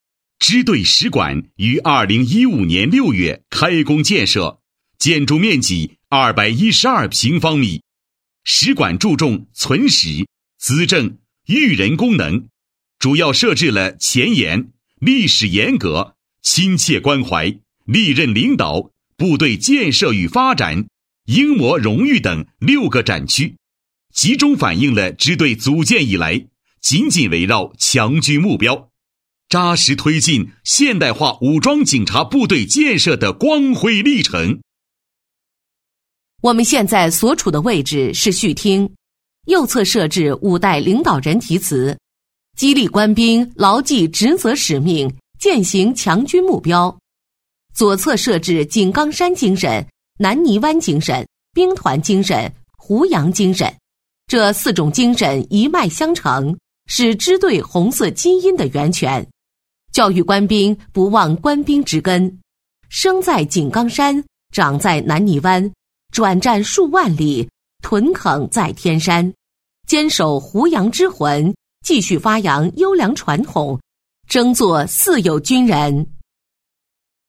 23 男国162_其他_对播_专题女国124 男国162